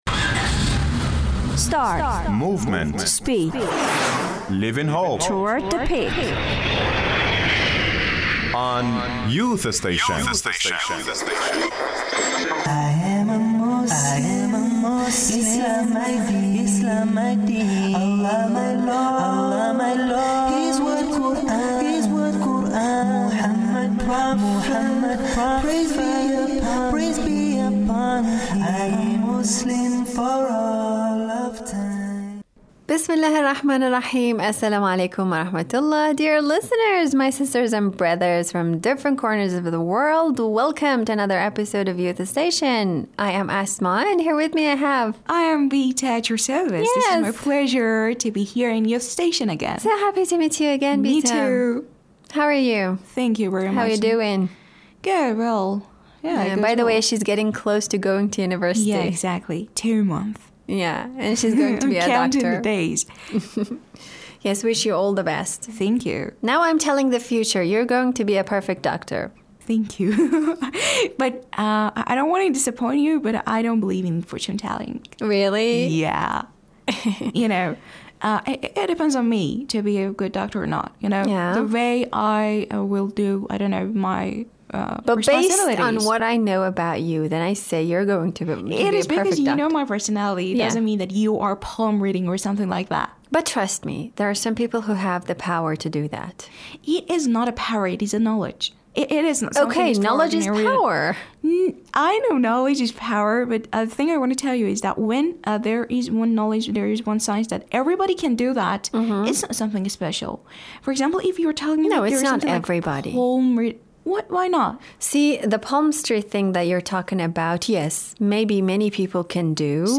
Muslim Youth speak about Fortune Teller